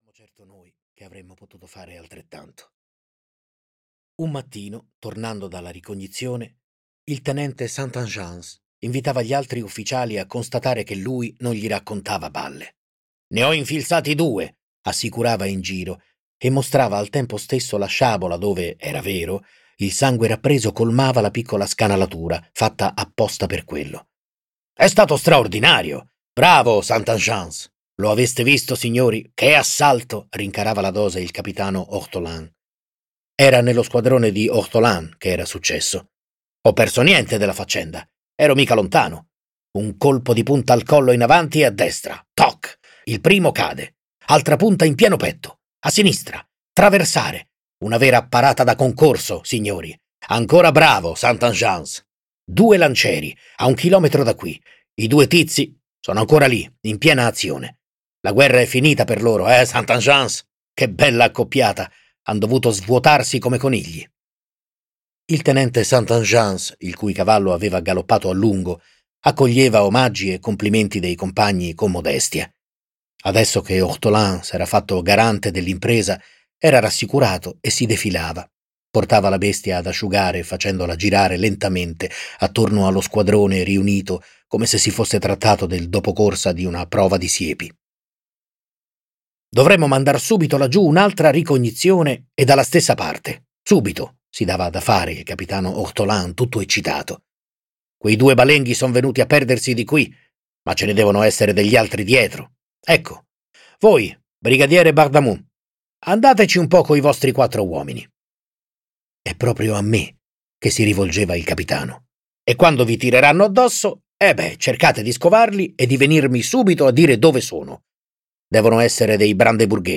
• Letto da: Stefano Fresi